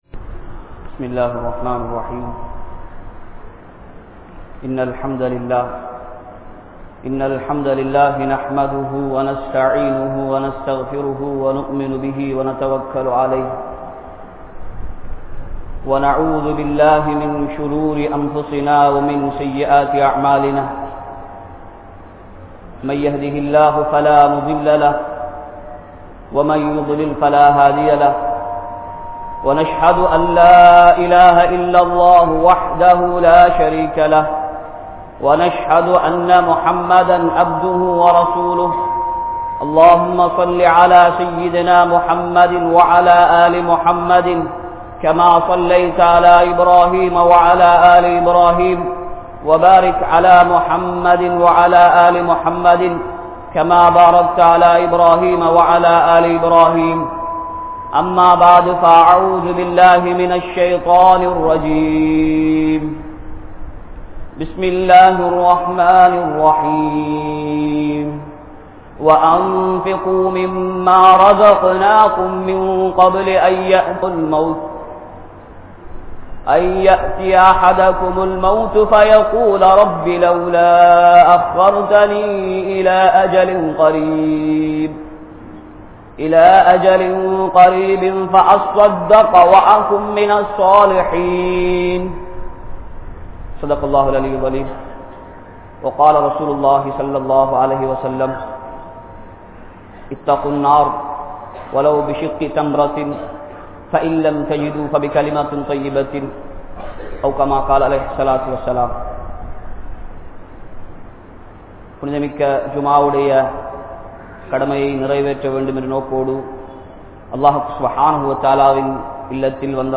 Sathakaavin Sirappuhal | Audio Bayans | All Ceylon Muslim Youth Community | Addalaichenai